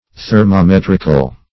Search Result for " thermometrical" : The Collaborative International Dictionary of English v.0.48: Thermometric \Ther`mo*met"ric\, Thermometrical \Ther`mo*met"ric*al\, a. [Cf. F. thermom['e]trique.] 1.